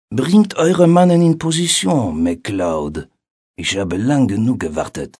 Strategy/simulation